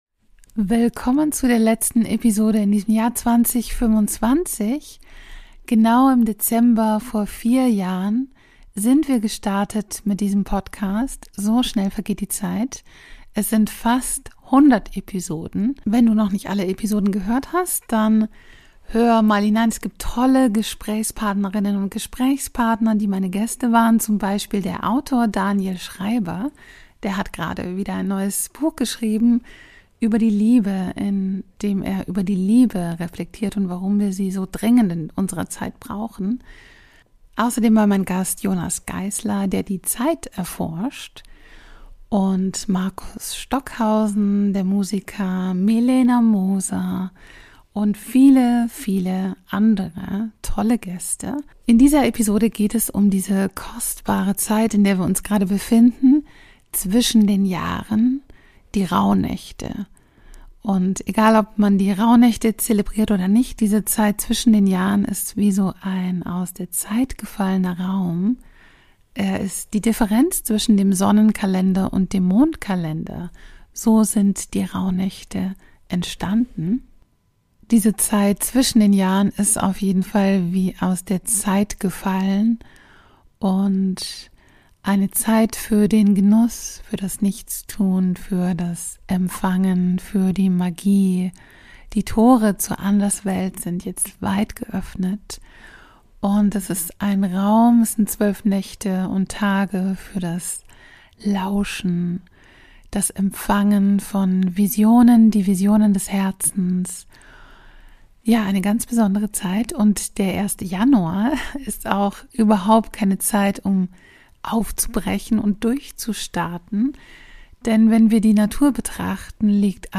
Impulse für die Zeit zwischen den Jahren und eine Meditation